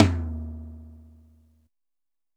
LOW-TOM 900.WAV